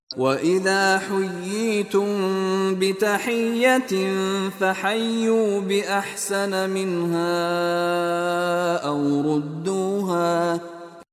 Contoh Bacaan dari Sheikh Mishary Rashid Al-Afasy
Kadar harakat bagi mad ini adalah 2 harakat.